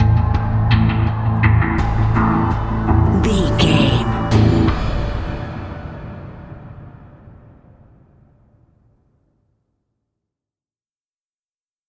Aeolian/Minor
synthesiser
drum machine
ominous
dark
suspense
haunting
creepy